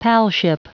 Prononciation du mot palship en anglais (fichier audio)
Prononciation du mot : palship